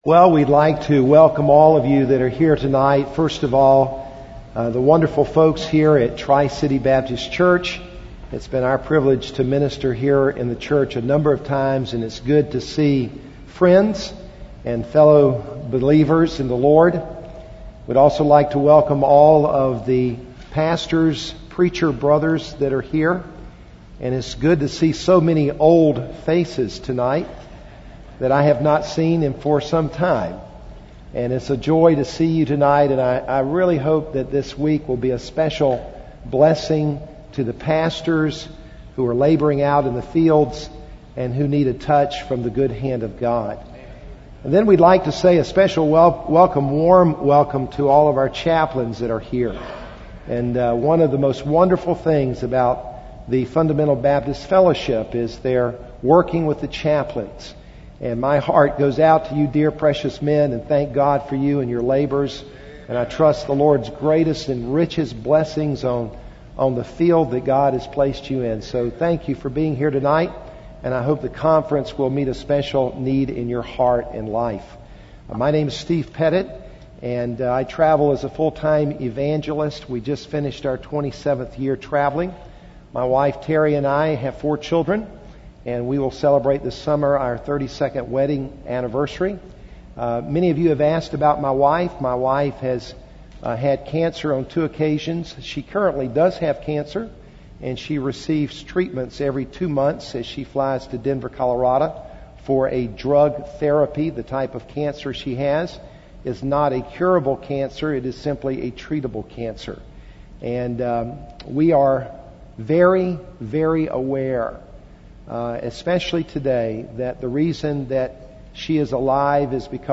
Series: FBFI Annual Meeting